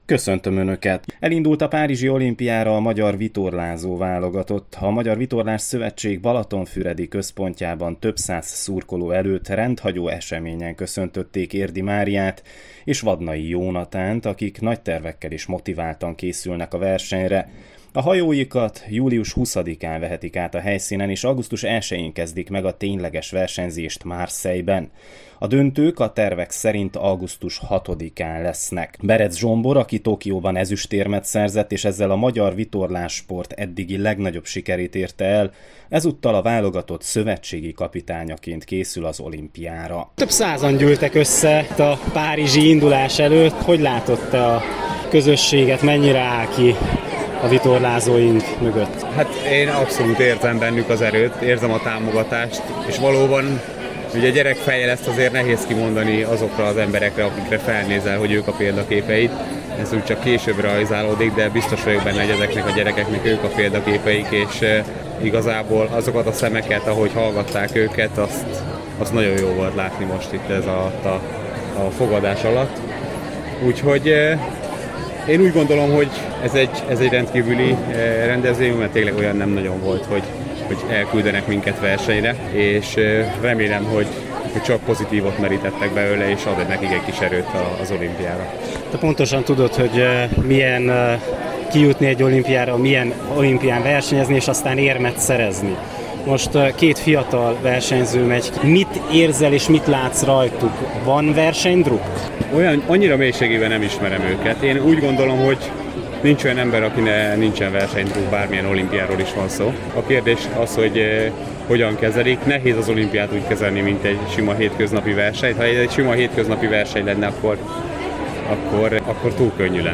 hallgassák meg az ott készült összeállítást. https